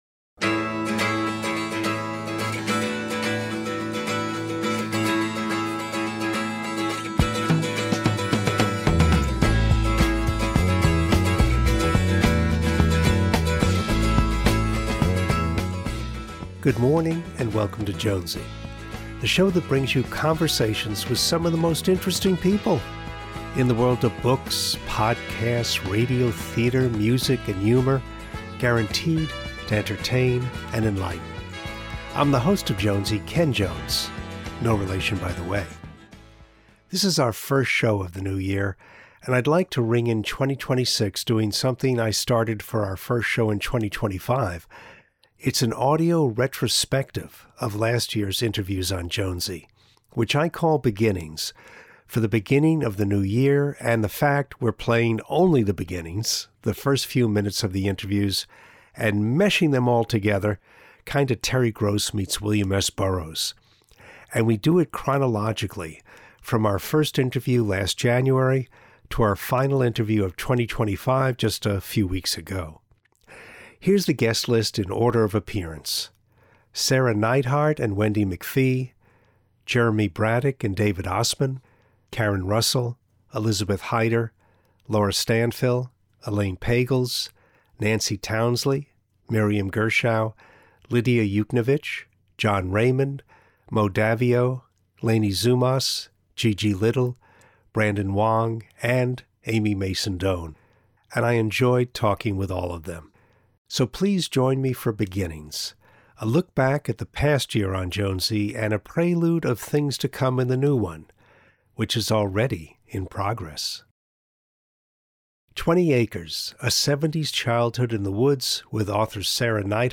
It’s an audio retrospective of last year’s interviews on Jonesy. Which I call Beginnings, for the beginning of the new year and the fact we’re playing only the beginnings, the first few minutes of the interviews, and meshing them all together.